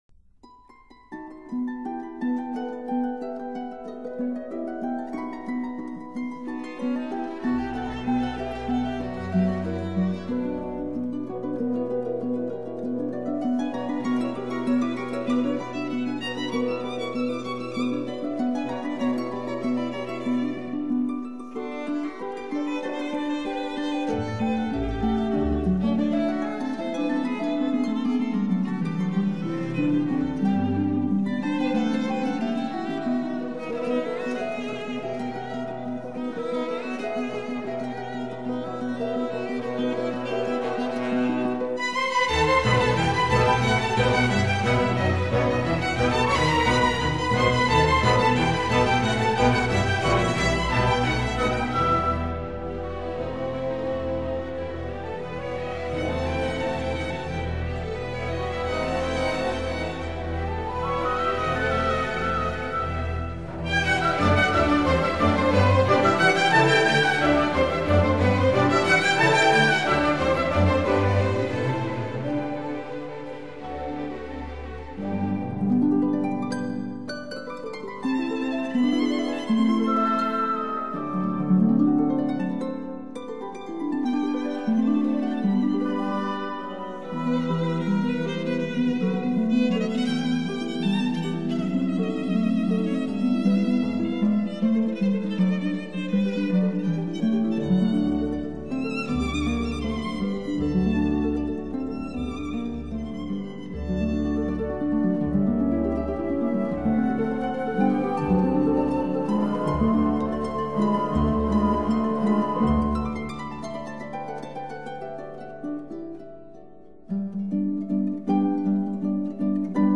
song for voice & piano
Rondo